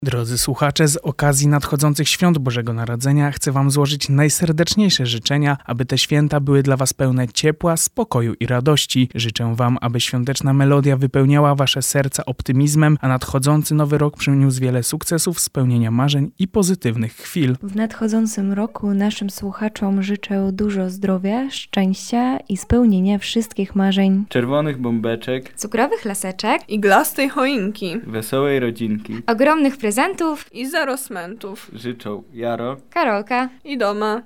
O to, czego chcieliby życzyć Wam- naszym drogim słuchaczom, zapytaliśmy reporterów redakcji informacyjno-kulturalnej Akademickiego Radia Centrum: